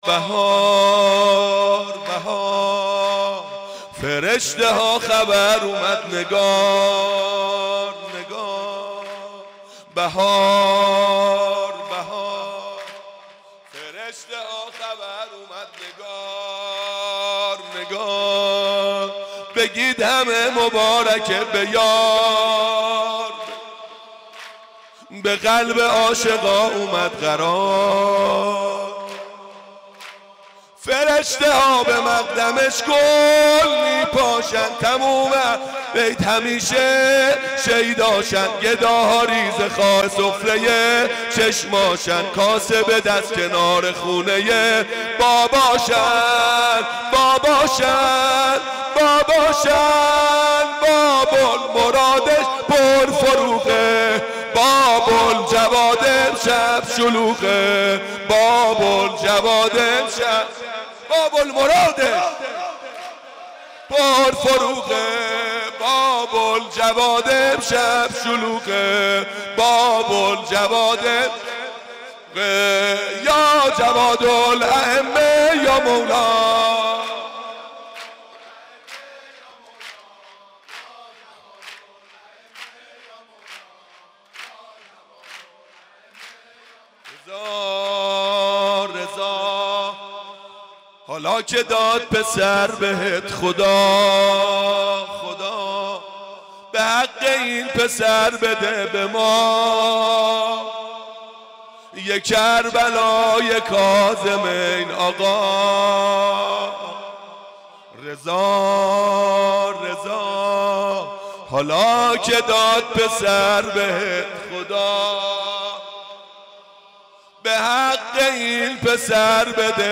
عقیق: مراسم جشن میلاد امام جواد (ع) با حضور جمعی از محبین اهل بیت(ع) در حسینیه بیت الزهرا(س) برگزار شد.